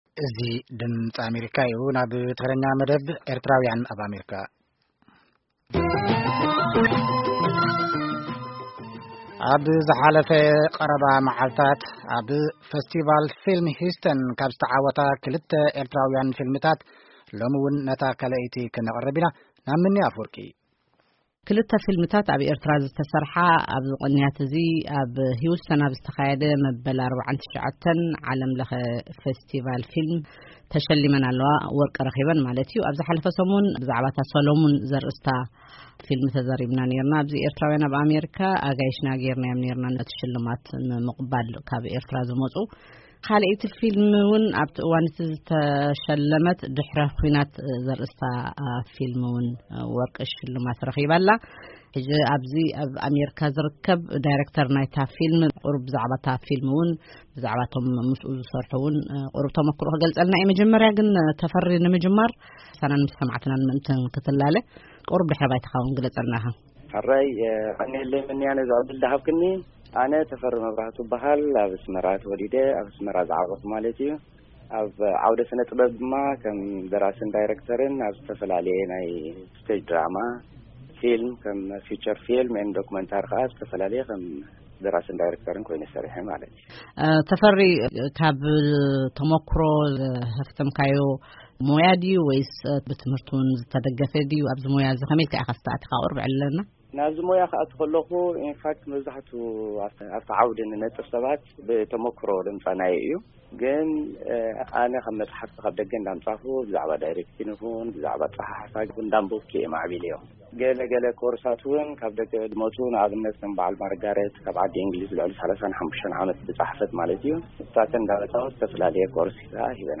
ቃለ-መጠይቕ